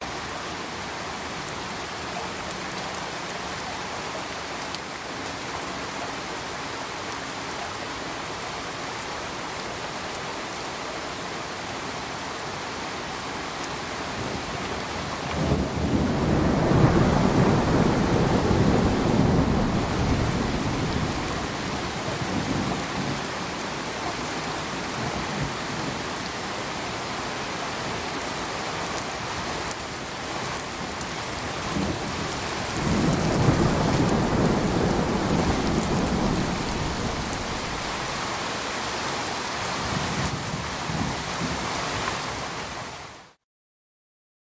破碎的机器人拨号器信息 " 奇怪的电话信息
描述：不确定它是系统故障还是对接拨号，但这种奇怪的节奏噪音可能对某些东西有用。直接录制在我的机器人上。
标签： 消息 屁股 电话 手机 移动 噪音
声道立体声